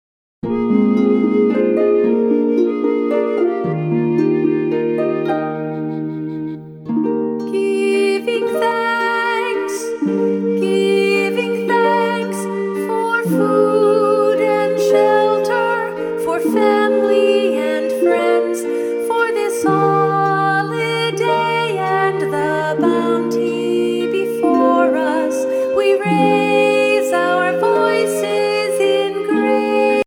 A Thanksgiving Song